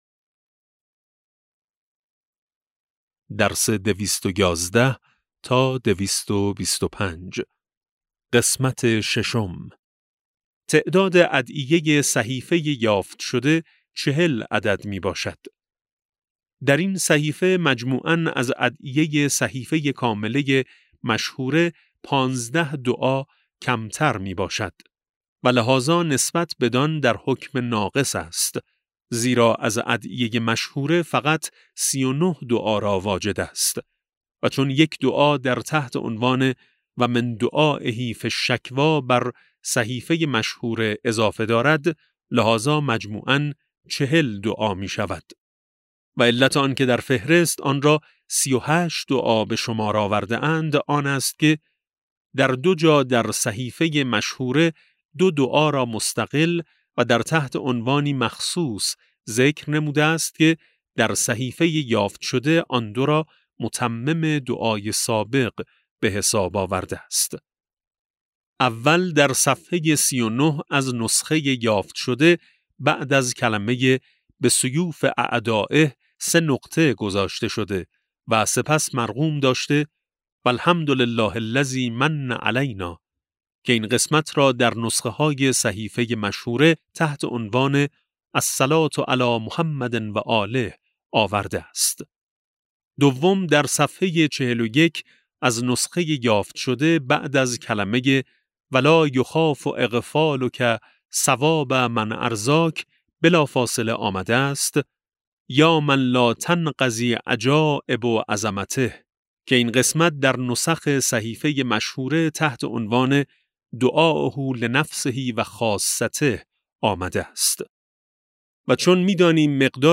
کتاب صوتی امام شناسی ج15 - جلسه6